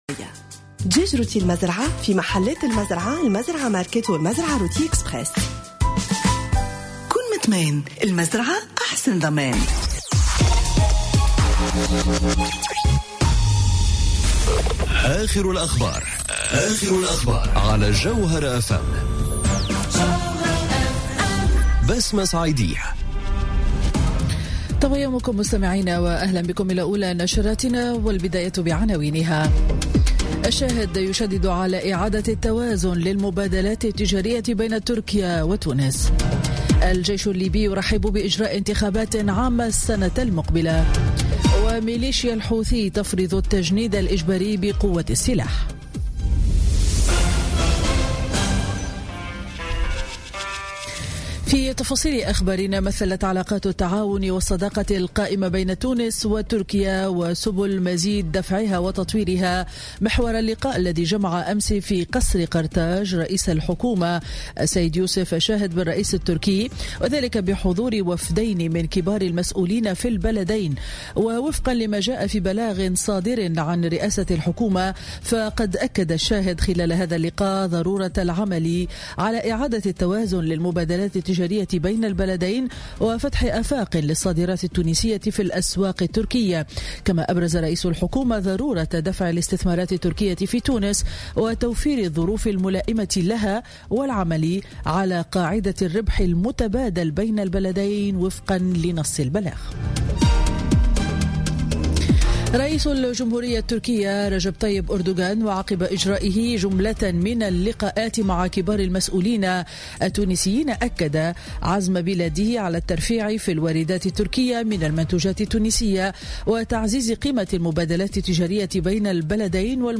نشرة أخبار السابعة صباحا ليوم الخميس 28 ديسمبر 2017